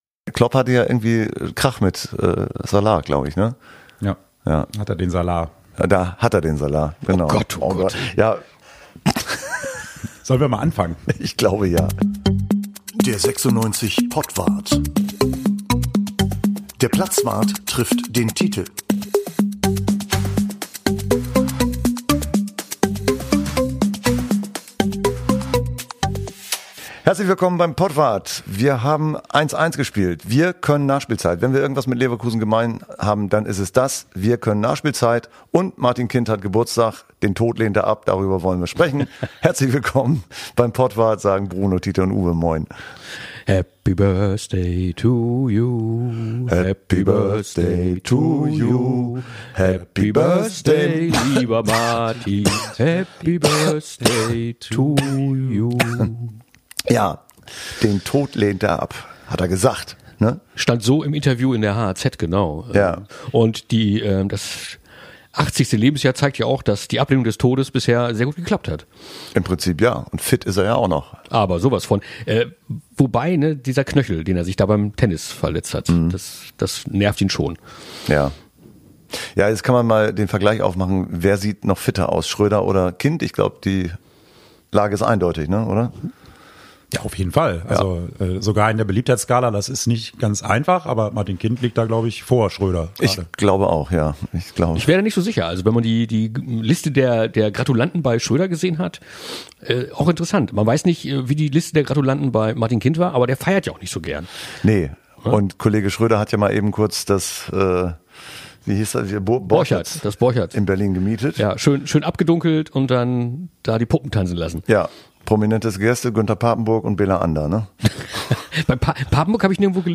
(Achtung: Dieser Podwart enthält Gesang. Die Töne können belastend oder retraumatisierend auf Hörer:innen wirken).